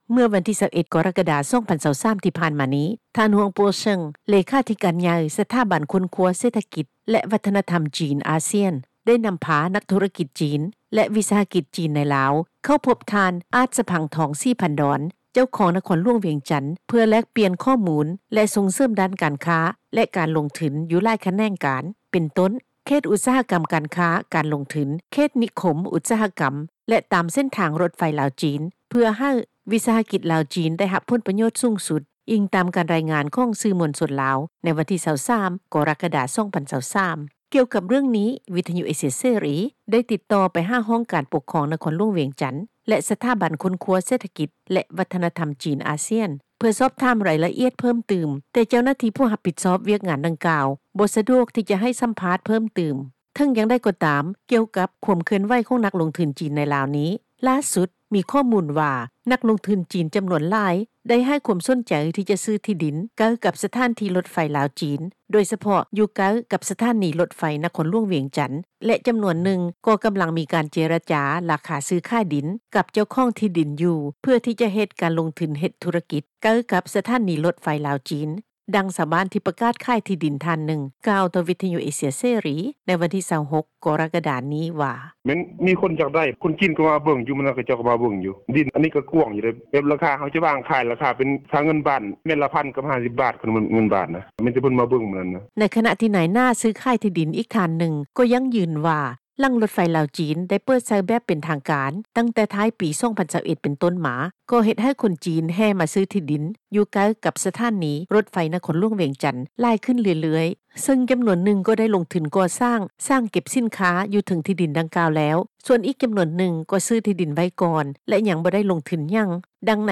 ນັກທຸຣະກິຈຈີນ ເຂົ້າພົບເຈົ້າຄອງ ນະຄອນຫຼວງວຽງຈັນ ເພື່ອສົ່ງເສີມທຸຣະກິຈຮ່ວມກັນ — ຂ່າວລາວ ວິທຍຸເອເຊັຽເສຣີ ພາສາລາວ
ດັ່ງ ນາຍໜ້າຊື້-ຂາຍດິນ ອີກທ່ານນຶ່ງ ກ່າວວ່າ:
ດັ່ງ ຊາວບ້ານທີ່ປະກາດຂາຍທີ່ດິນ ອີກນາງນຶ່ງ ກ່າວວ່າ:
ດັ່ງ ເຈົ້າໜ້າທີ່ກະຊວງແຜນການ ແລະ ການລົງທຶນ ທ່ານນຶ່ງ ກ່າວວ່າ: